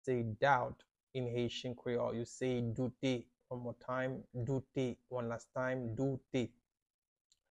How to say “Doubt” in Haitian Creole – “Doute” pronunciation by a native Haitian teacher
“Doute” Pronunciation in Haitian Creole by a native Haitian can be heard in the audio here or in the video below: